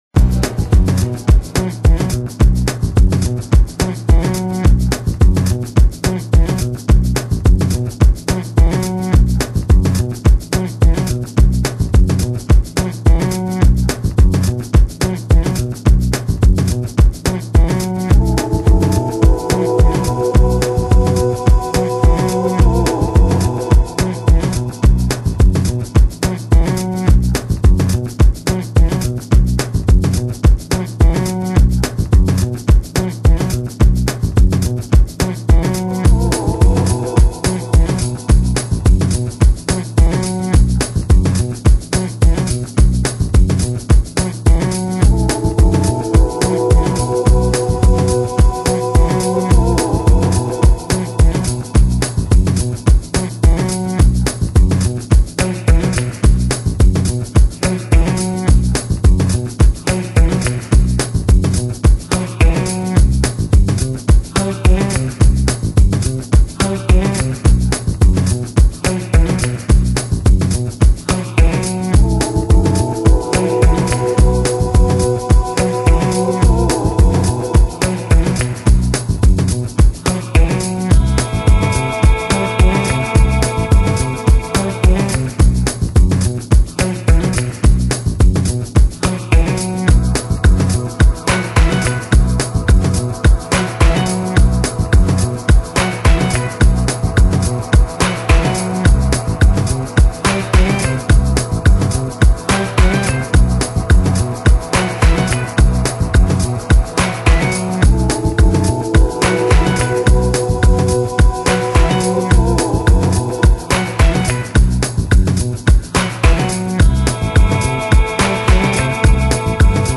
Genre: Lounge